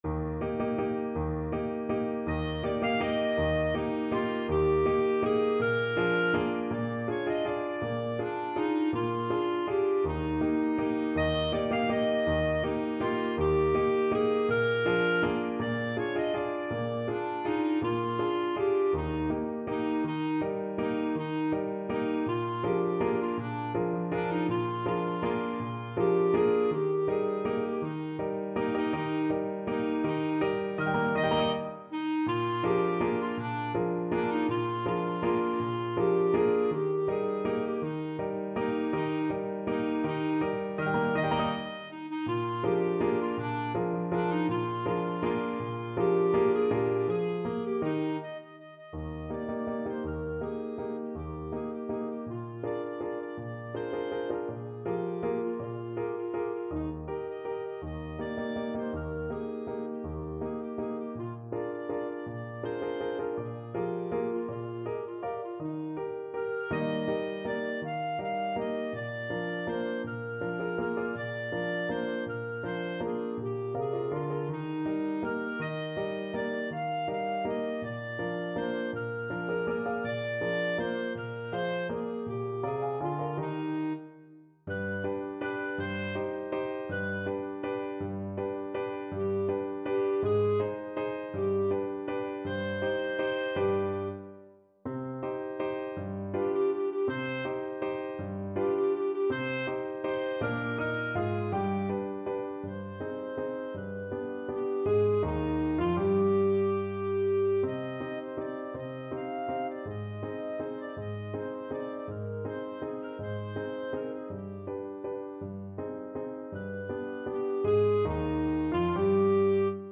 Clarinet 1Clarinet 2
Lively one in a bar . = c. 54
3/4 (View more 3/4 Music)
Classical (View more Classical Clarinet Duet Music)
world (View more world Clarinet Duet Music)